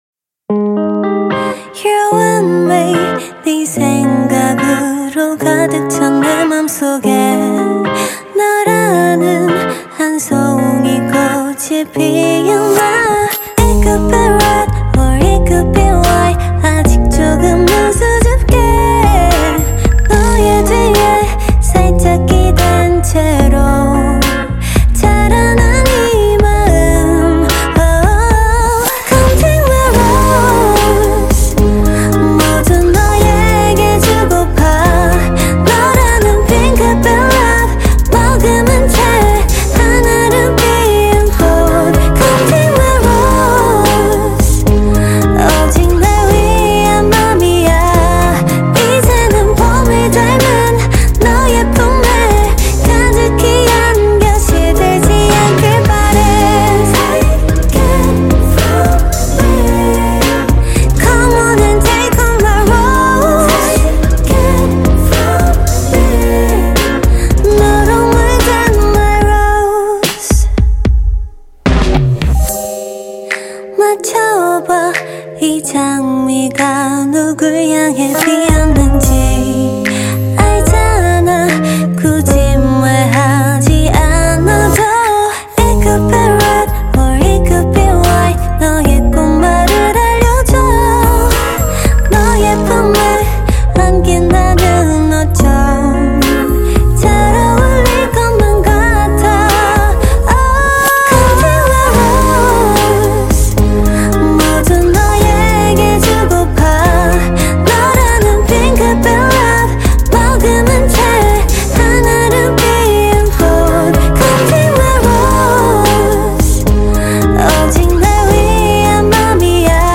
شاد و غمگین یکجا
ژانر و سبک کی پاپ هیپ هاپ ئی‌دی‌ام
پلی لیست تمام آهنگ های گروه دخترانه اهل کره جنوبی